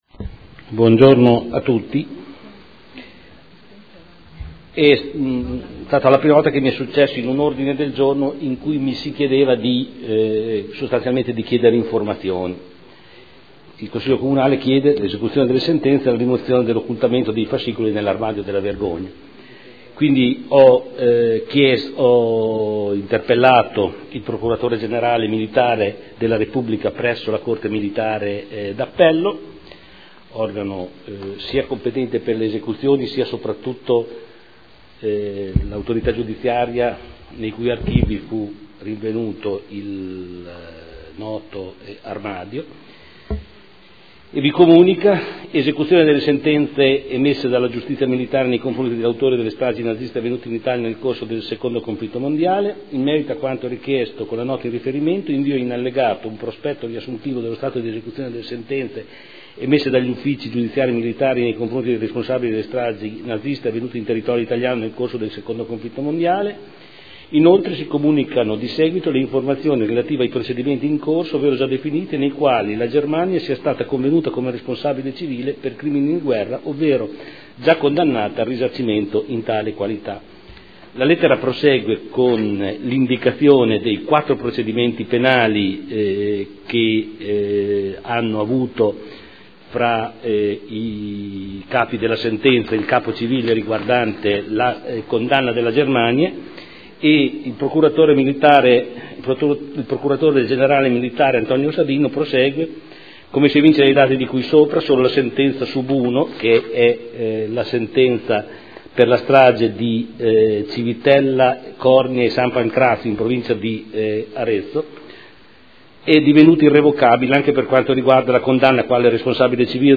Seduta del 17/06/2013. Comunicazione su aggiornamento a un Ordine del Giorno votato dal Consiglio sul tema "Armadio della vergogna"